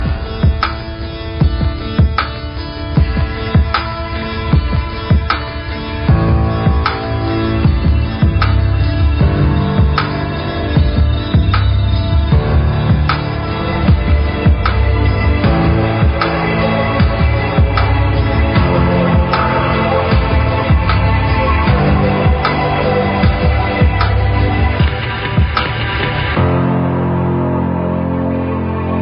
Cântece tematice